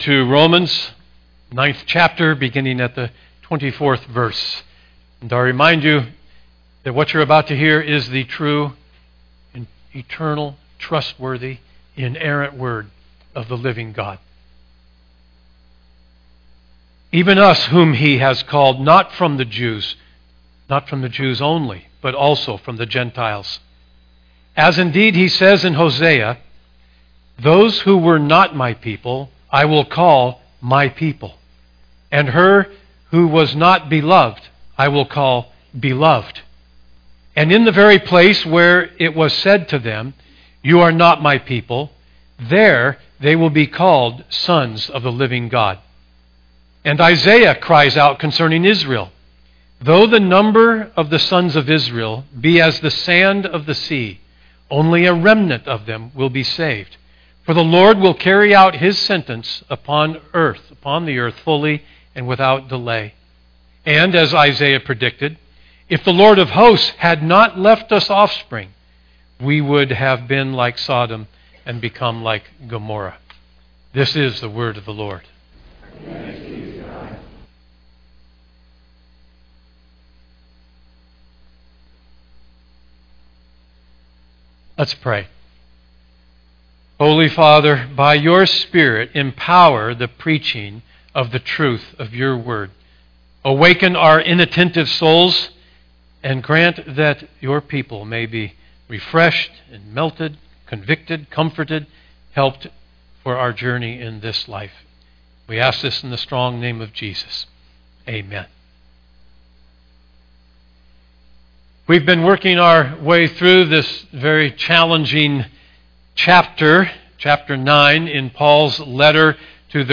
Download Sermon Notes Listen & Download Audio Series